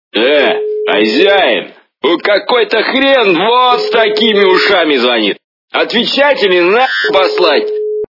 » Звуки » Смешные » Говорящий телефон - Эй, хозяин. Тут какой-то хрен с большими ушами звонит...
При прослушивании Говорящий телефон - Эй, хозяин. Тут какой-то хрен с большими ушами звонит... качество понижено и присутствуют гудки.